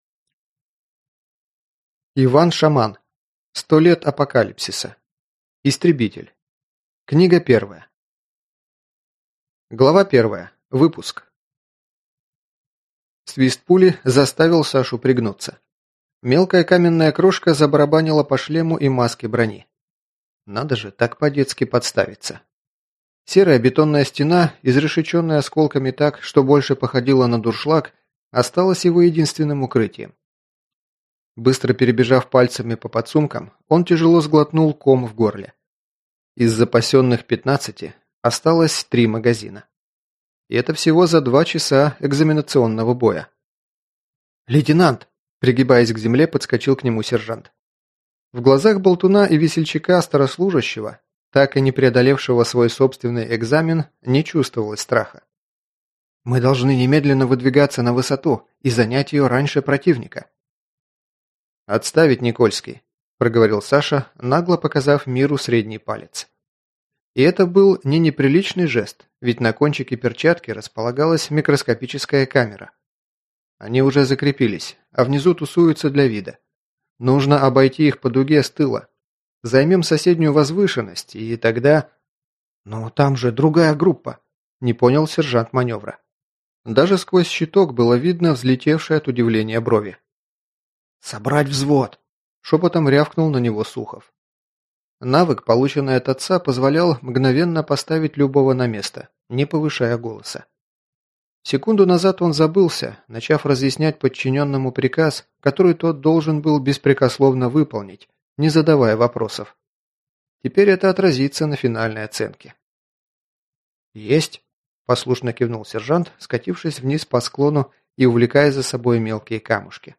Аудиокнига Истребитель. Книга 1 | Библиотека аудиокниг